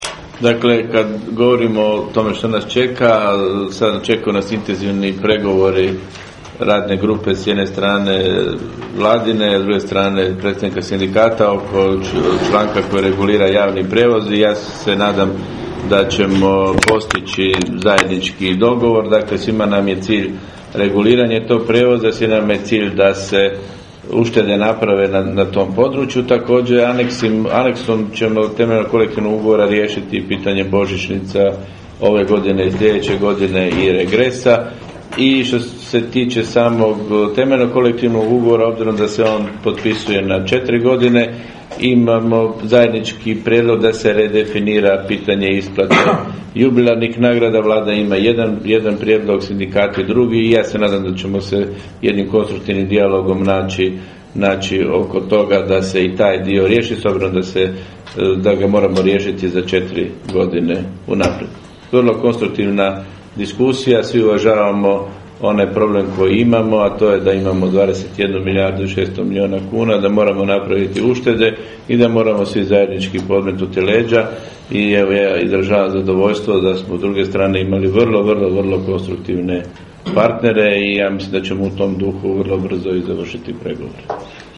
izjava ministra rada i mirovinskog sustava g. Miranda Mrsića